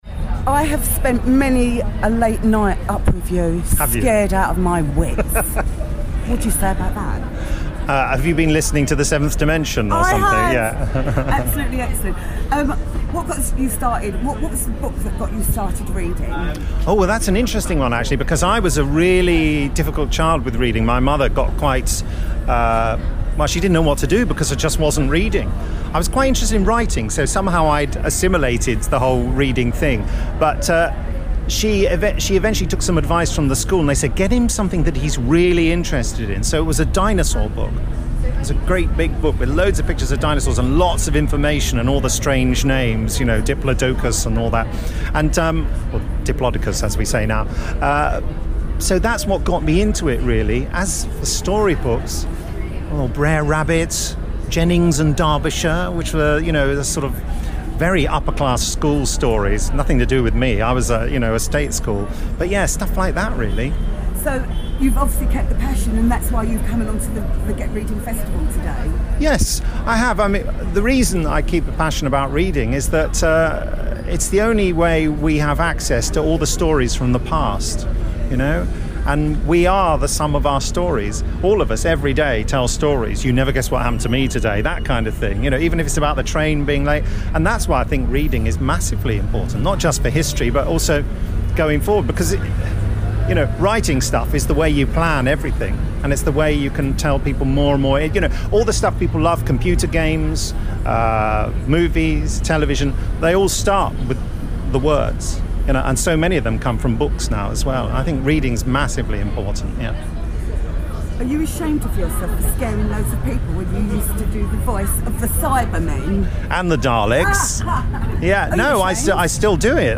At the Evening Standard’s Get Reading Festival in Trafalgar Square, I caught up with the voice of the Daleks and the Cyberman Nick Briggs.